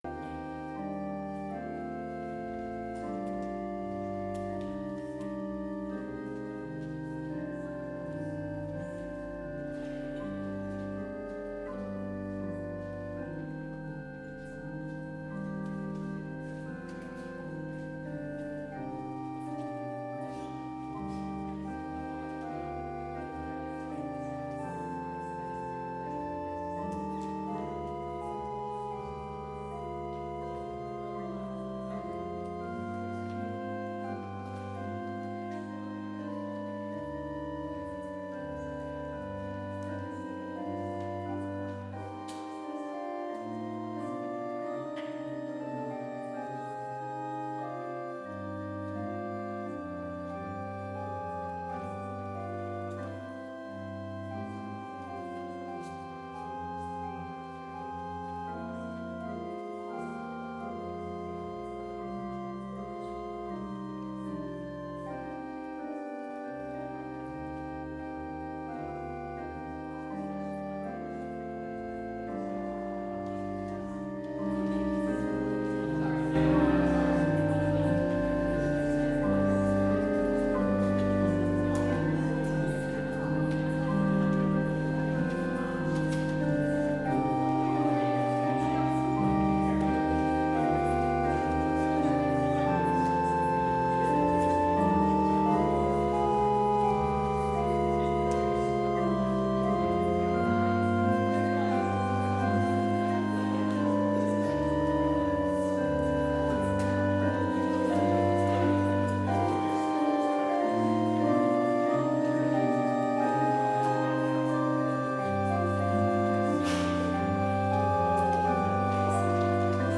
Complete service audio for Chapel - March 22, 2022
Hymn 298 - Sing, My Tongue, How Glorious Battle